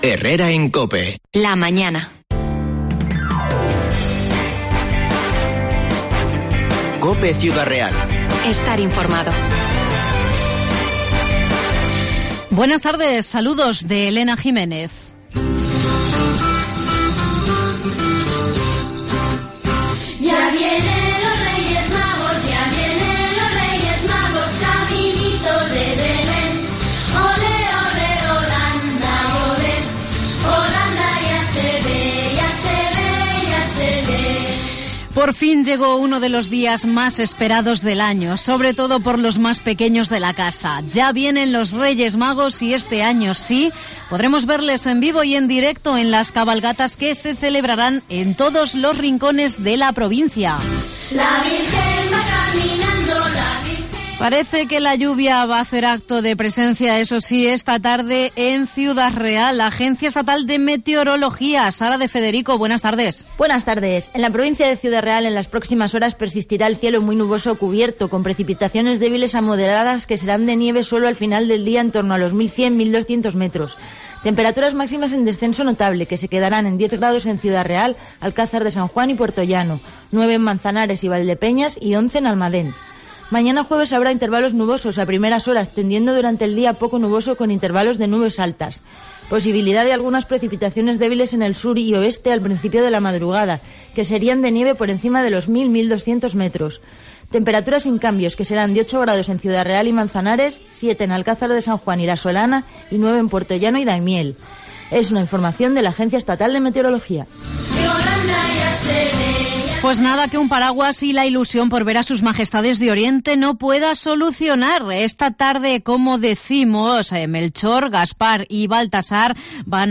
Reportaje Reyes Magos 2022